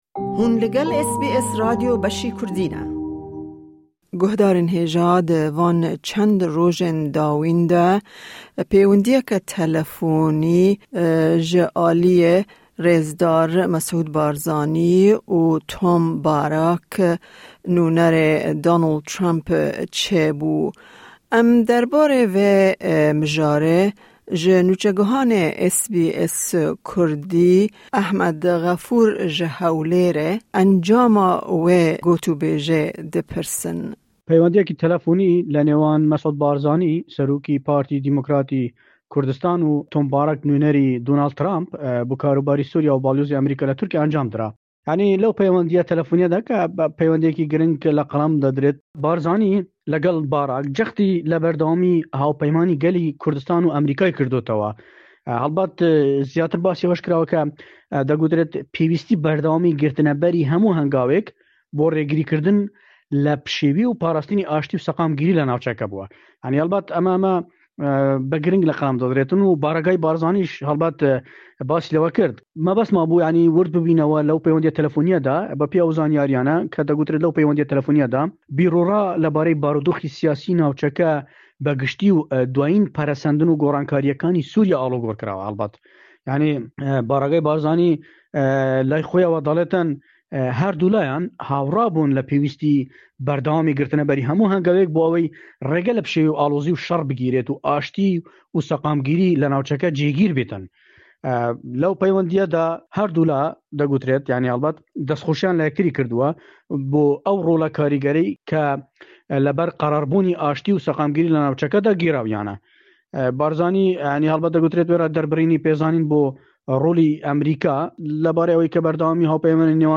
Raportên Peyamnêran